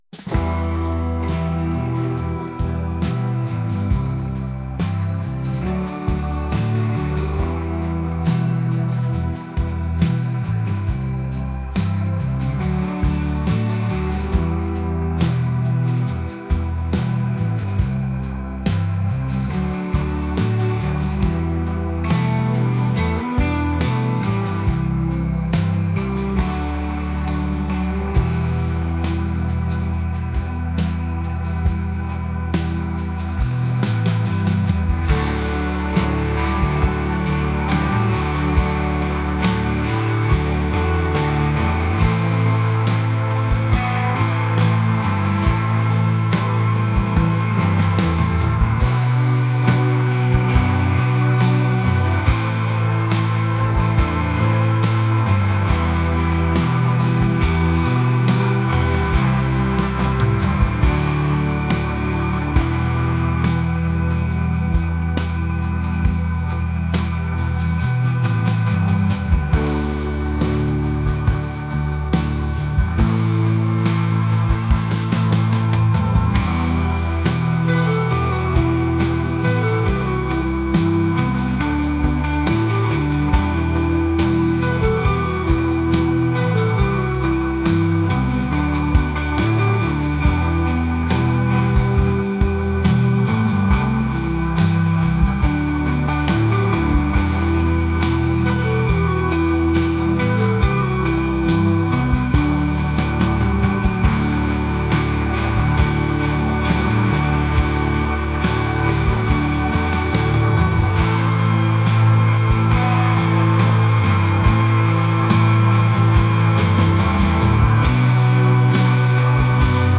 rock | indie